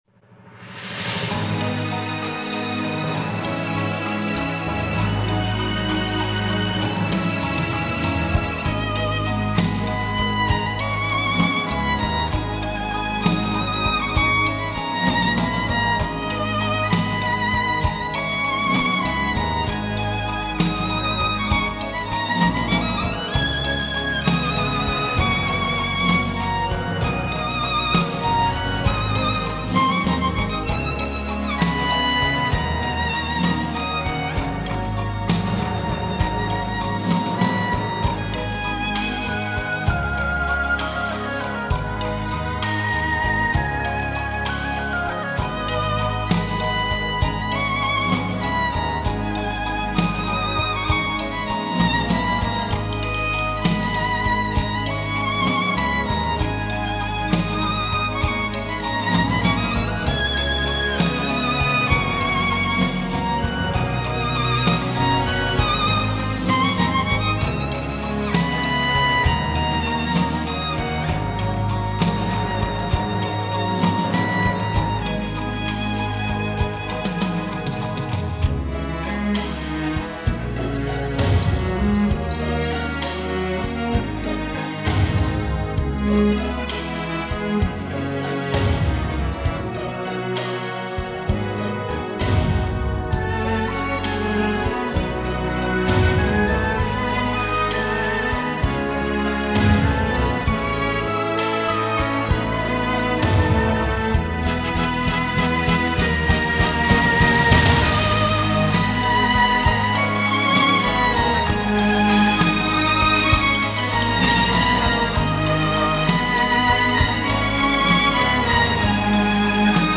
[纯音乐]
这是一个旋律优美悲壮凄凉的小提琴协奏曲